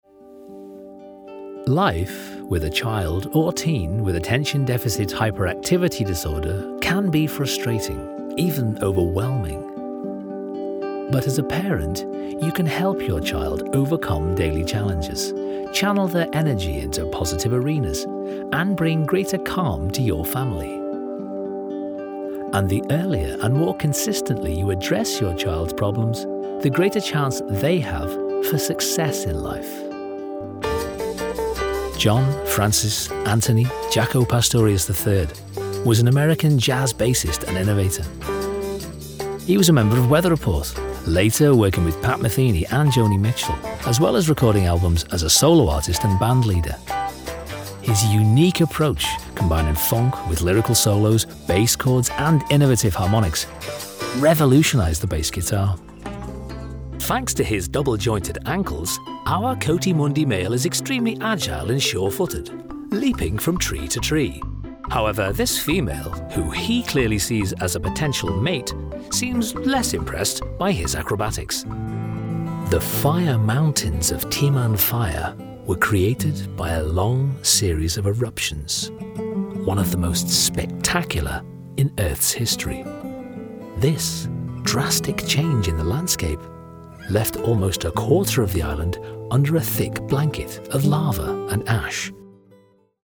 Englischer Sprecher, Bass-Baritone, neutral bis nördlich UK, Werbung, Off-Sprecher, warm, reliable, corporate/explainer
Sprechprobe: Sonstiges (Muttersprache):
Native English speaker (UK), bass-baritone, accent neutral or northern (Liverpool). Warm, relatable.